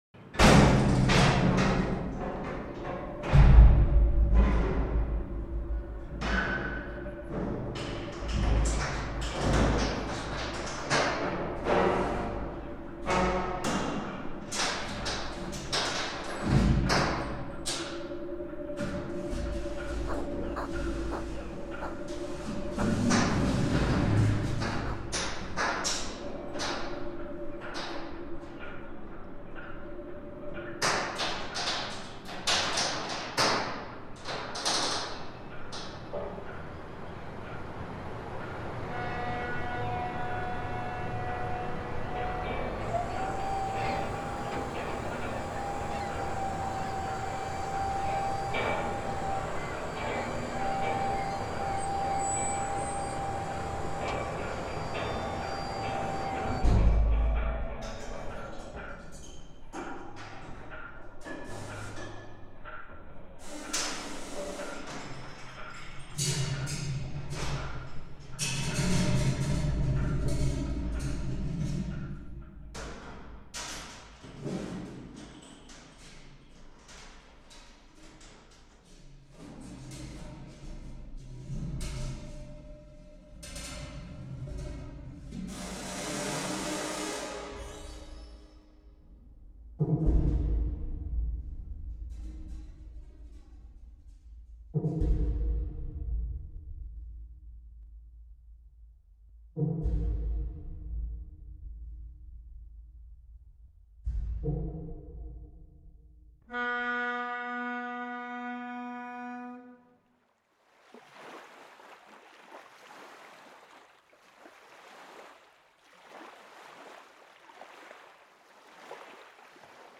It involved song and dance, a large cast, 16 shipping containers, 40-foot projections, 4-channel sound, an audience capacity of 400, contraptions and more. 4[4.
“Voyage” audio excerpt from Fall Away Home (2013) Boca del Lupo during Stanley Park 125th Celebrations in Stanley Park Vancouver on 1 September 2013.
The moment in the show that tickled the electroacoustician in me the most was when the audience put on blindfolds and listened to a three-minute sonic journey across the Pacific Ocean, which in another context might be perceived as an acousmatic concert.